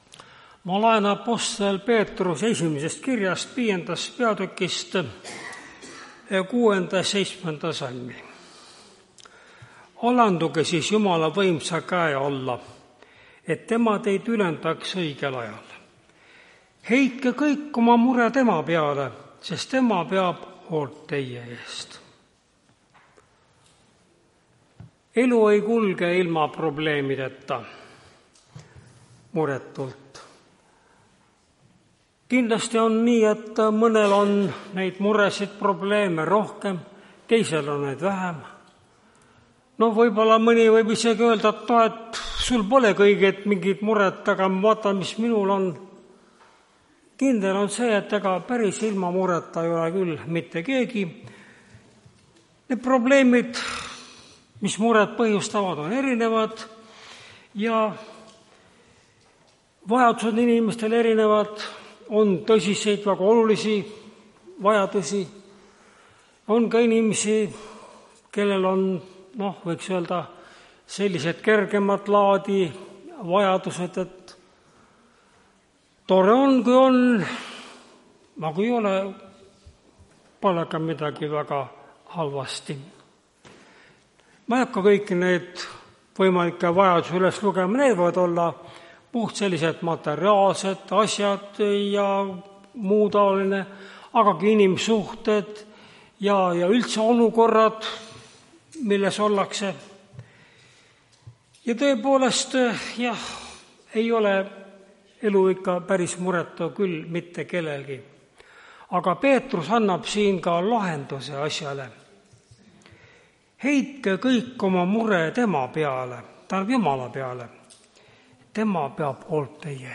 Tartu adventkoguduse 16.11.2024 hommikuse teenistuse jutluse helisalvestis.